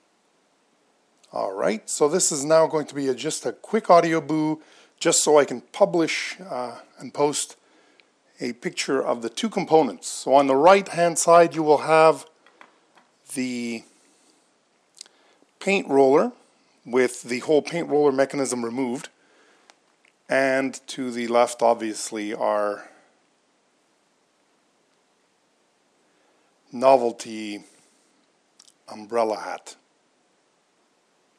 DIY Parabolic microphone part 2